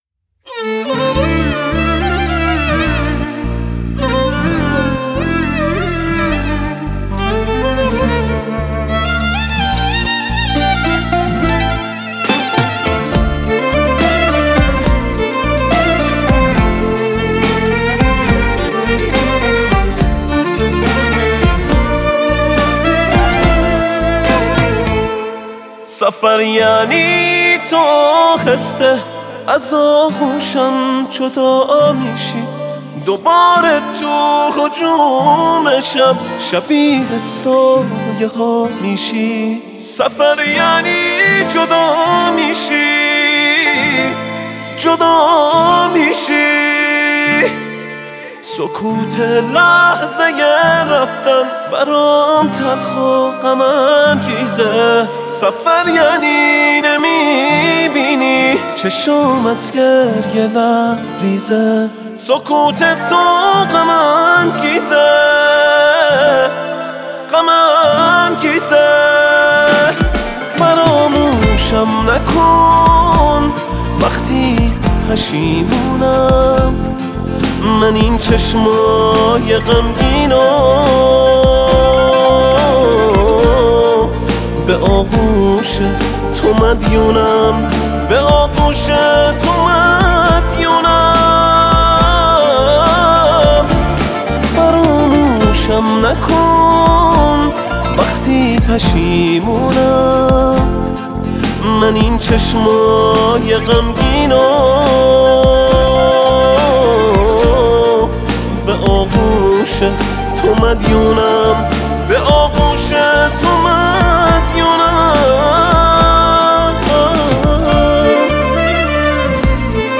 تک آهنگ
پاپ